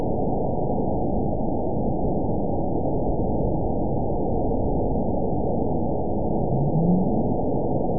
event 920484 date 03/27/24 time 22:46:47 GMT (1 year, 9 months ago) score 9.61 location TSS-AB02 detected by nrw target species NRW annotations +NRW Spectrogram: Frequency (kHz) vs. Time (s) audio not available .wav